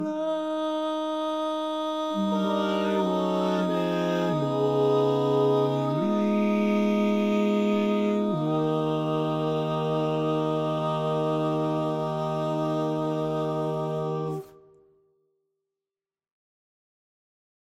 Key written in: E♭ Major
How many parts: 4
Type: Barbershop
All Parts mix:
Learning tracks sung by